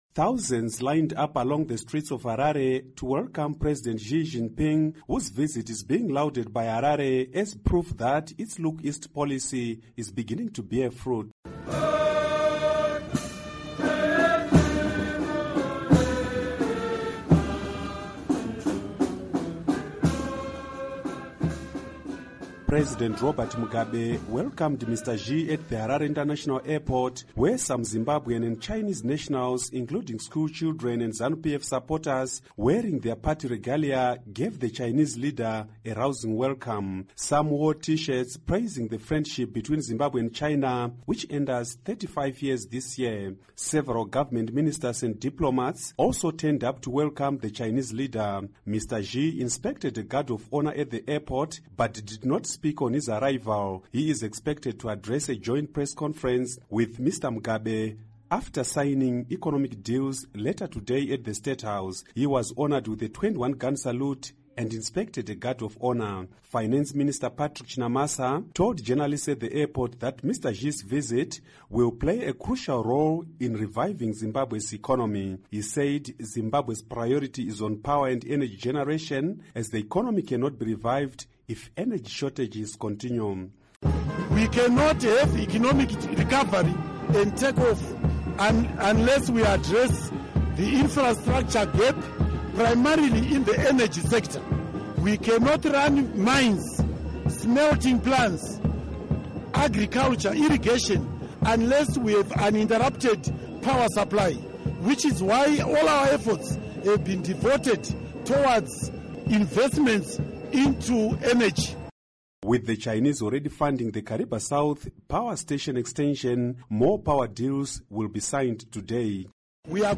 Report on President Xi JinPing's Visit to Zimbabwe